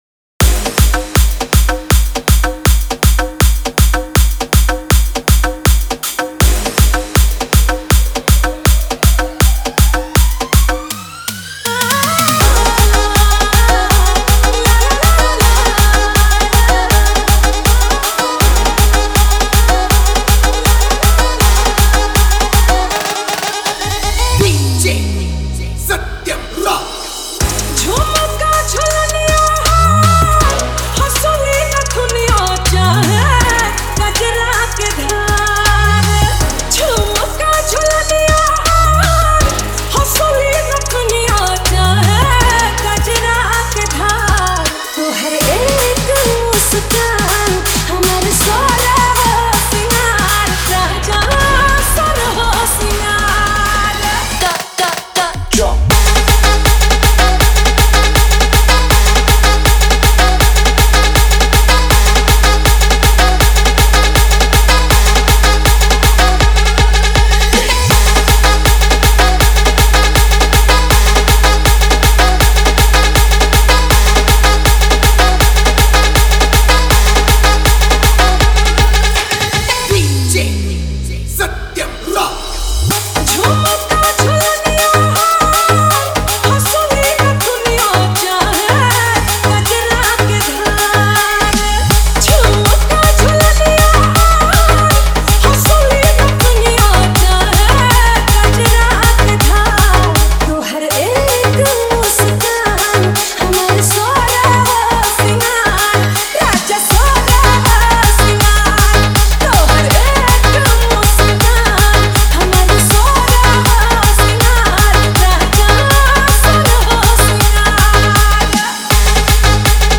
Bhojpuri DJ Remix Songs